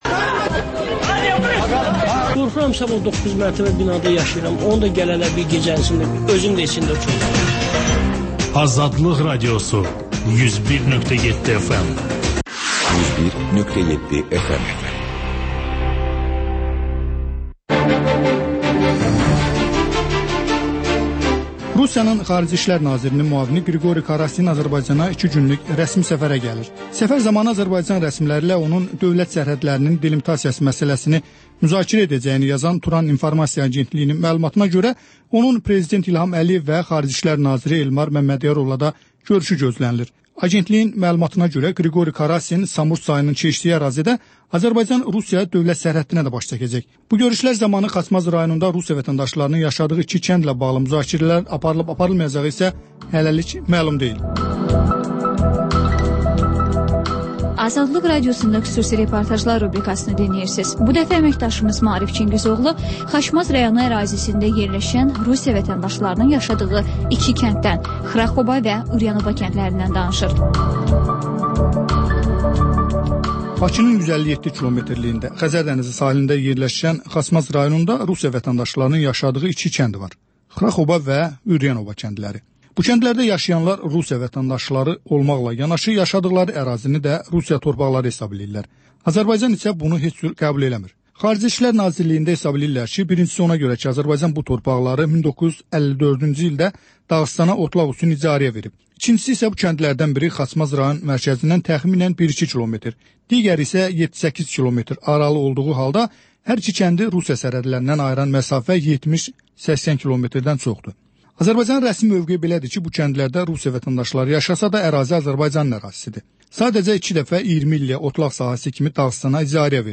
Müxbirlərimizin həftə ərzində hazırladıqları ən yaxşı reportajlardan ibarət paket